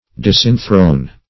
Disenthrone \Dis`en*throne"\, v. t.